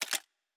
Weapon 02 Foley 3.wav